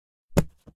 ebook_open.mp3